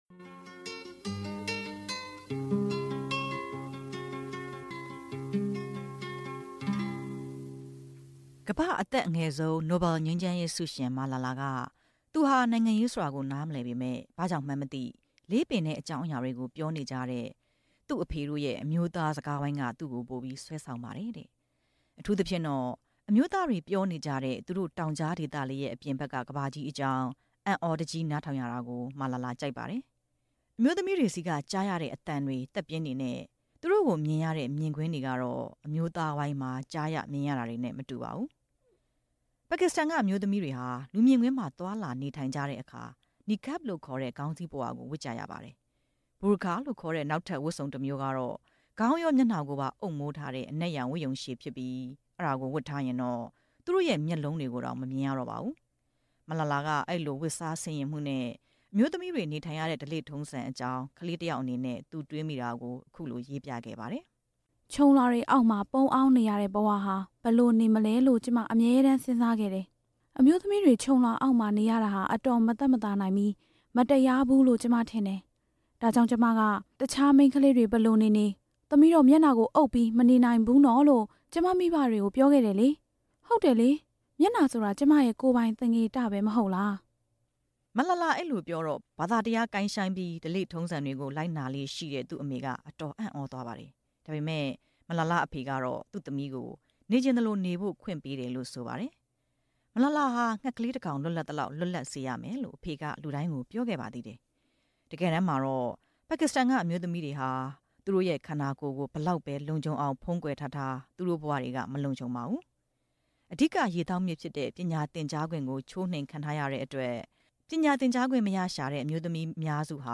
တက္ကသိုလ်ဆရာ၊ ဆရာမတွေ ရာထူးတိုးကိစ္စ မေးမြန်းချက်